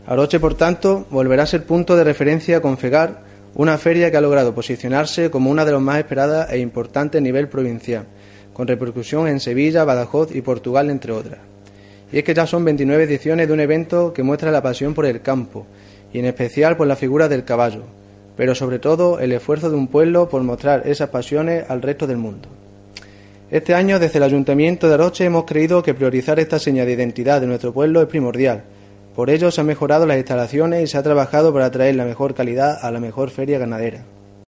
Adán Candeas, concejal de Festejos, Cultura y Juventud del Ayuntamiento de Aroche